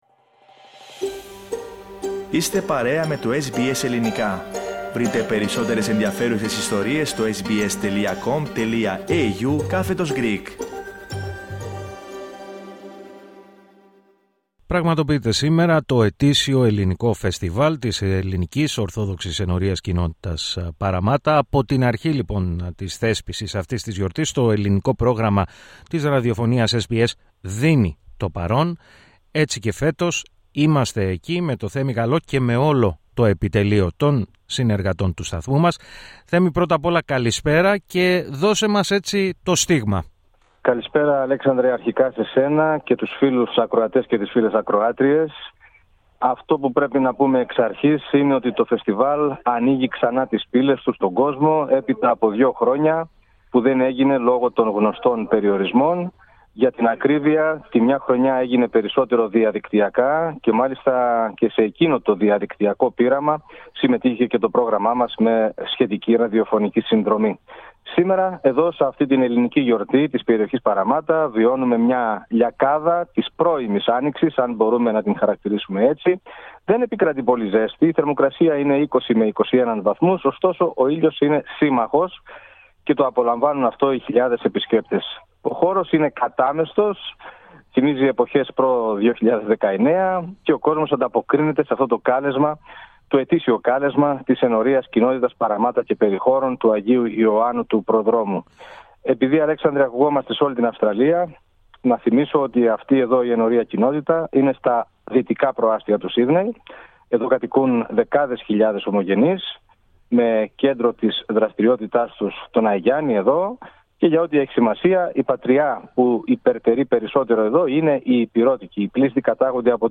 Πραγματοποιήθηκε σήμερα το ετήσιο Ελληνικό Φεστιβάλ της Ελληνικής Ορθόδοξης Ενορίας-Κοινότητας Parramatta.